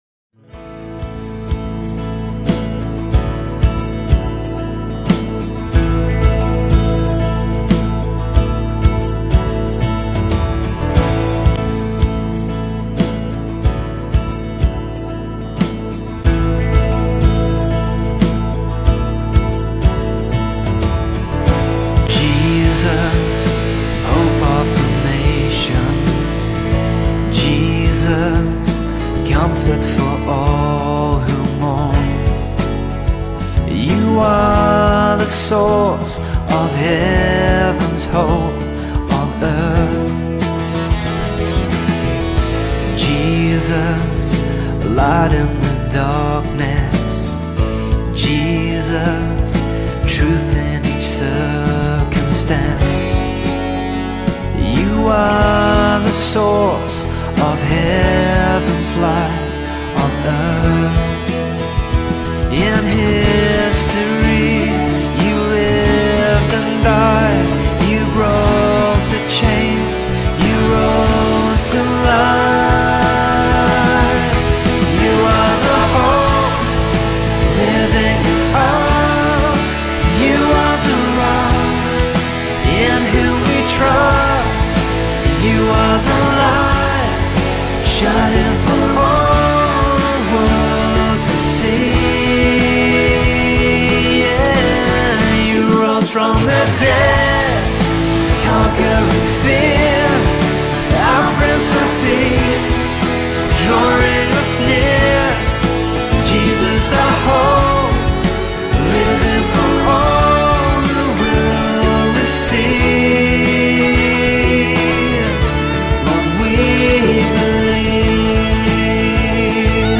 Welcome to this time of worship.